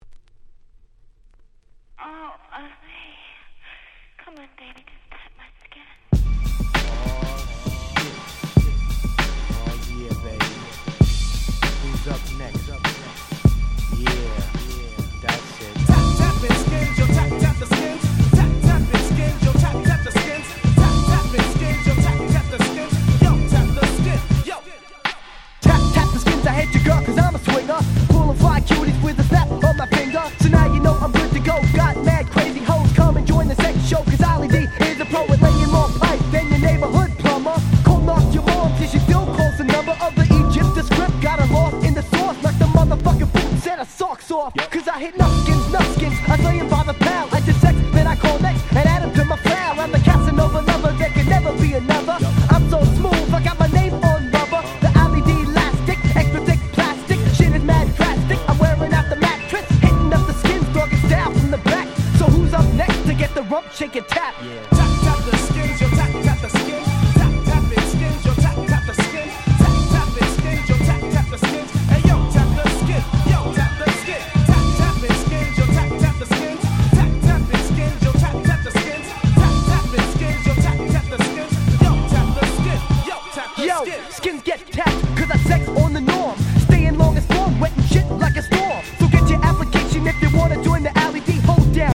93' Very Nice Hip Hop !!
90's ニュースクール Boom Bap ブーンバップ